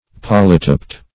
polytyped.mp3